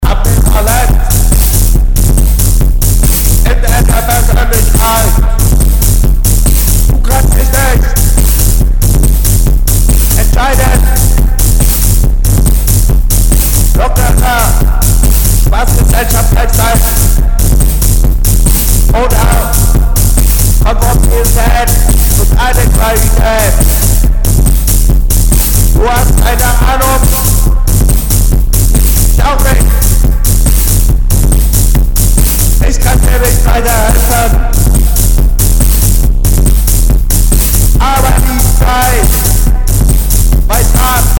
Esi Juli@ Soundkarte nimmt das Signal nur leise auf. Bitte um Hilfe